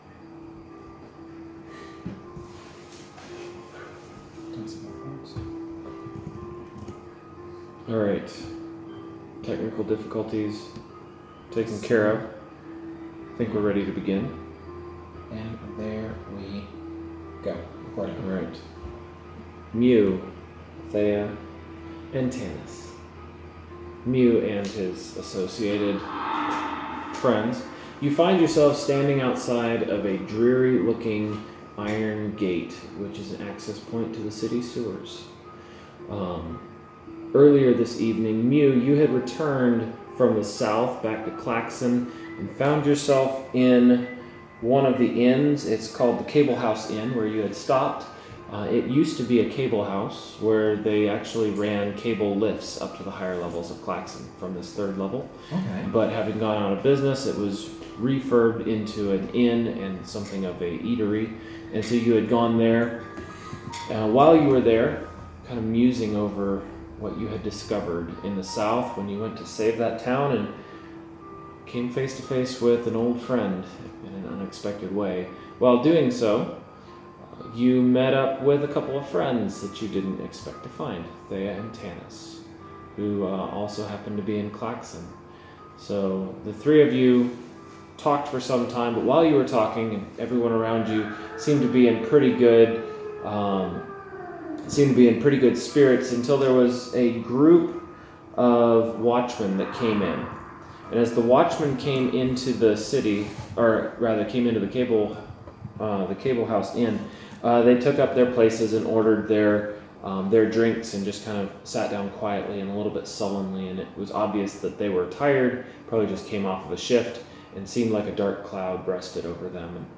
The party takes on a beholder! This short was recorded locally as the players gathered together around the same table.